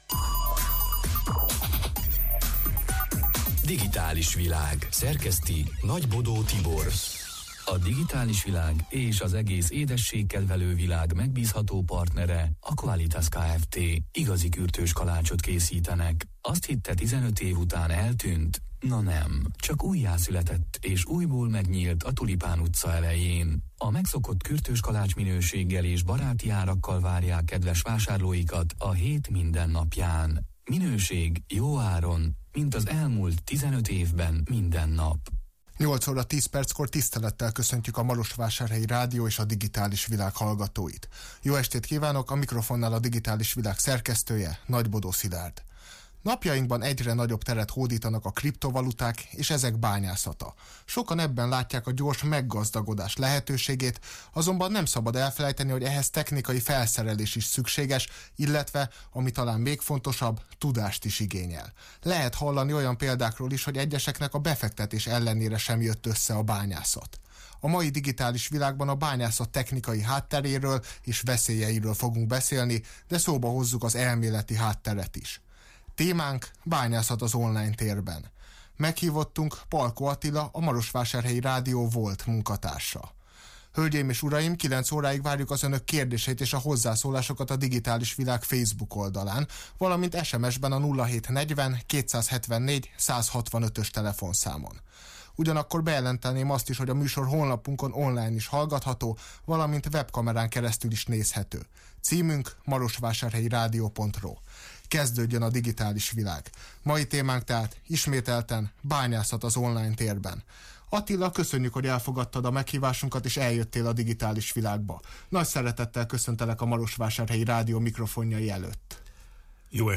(elhangzott élőben: 2021. június 15-én, kedden este nyolc órától)